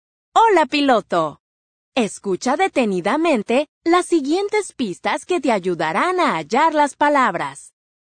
sw_host_hello_audio.mp3